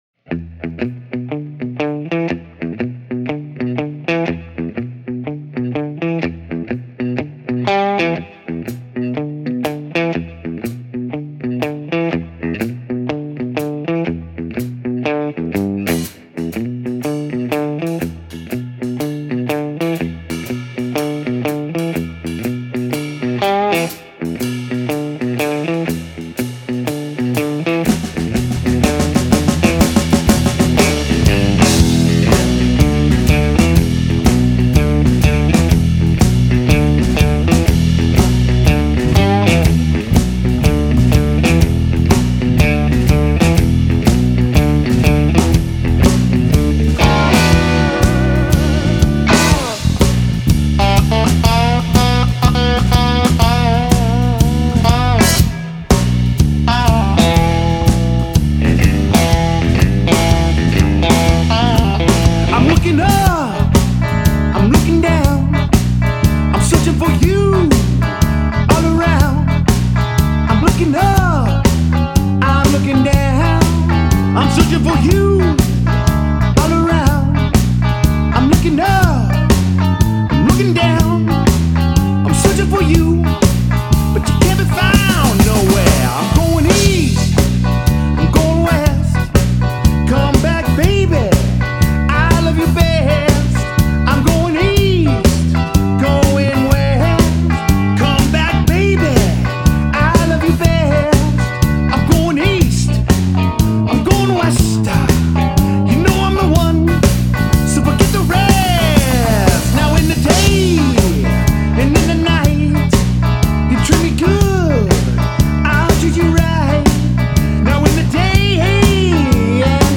Тип альбома: Студийный
Жанр: Blues-Rock